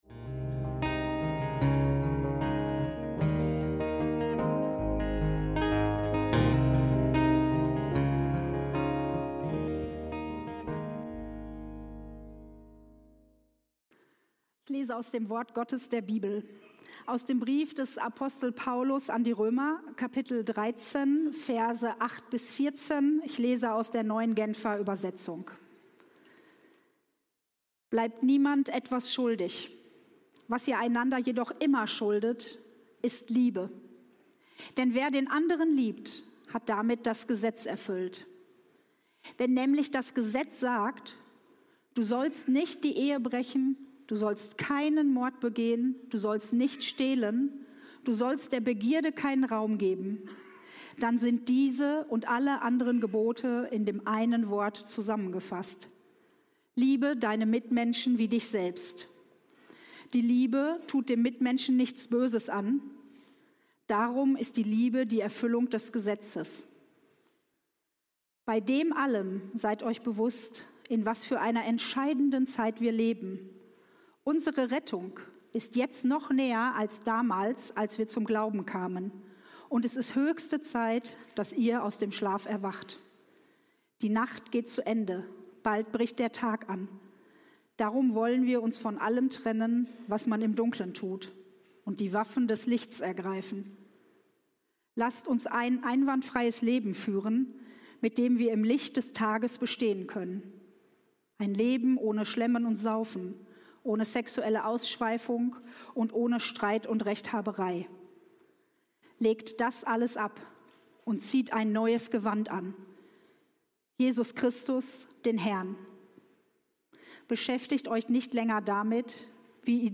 Die Nacht ist vorgedrungen - Predigt vom 14.12.2025